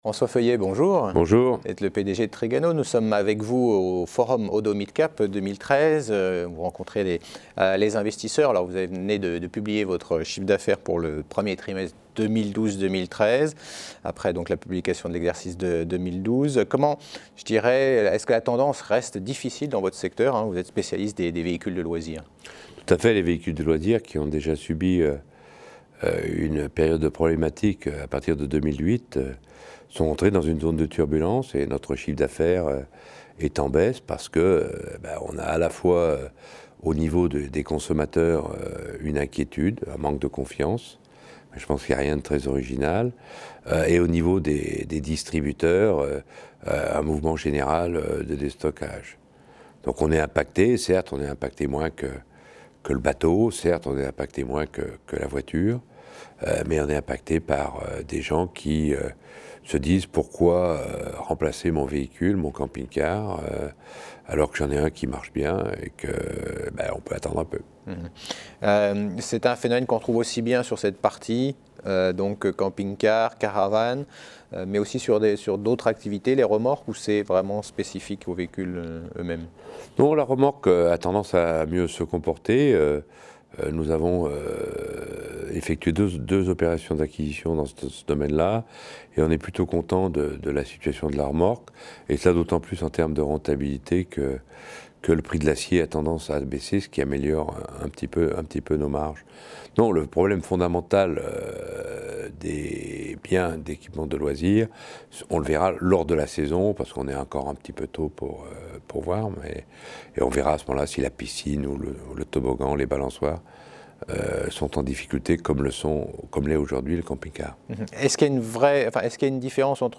Oddo Midcap Forum 2013 : Stratégie du spécialiste des équipements de loisirs